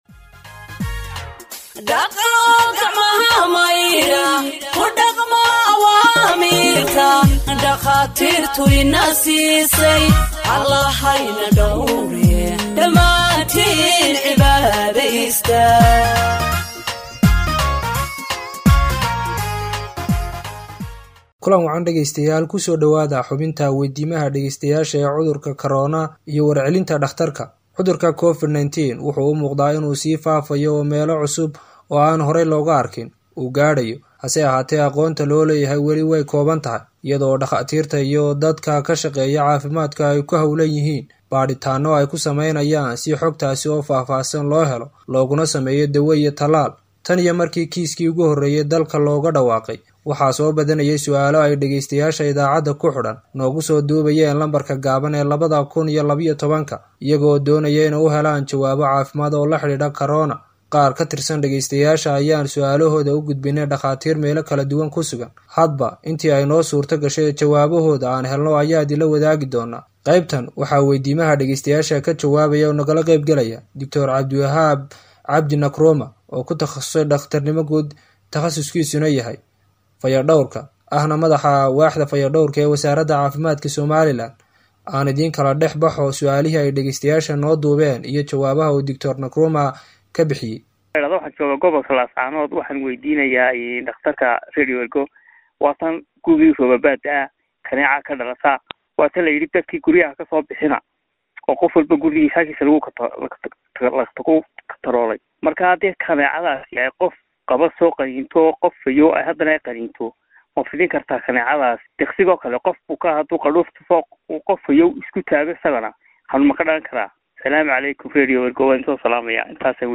Health expert answers listeners’ questions on COVID 19 (5)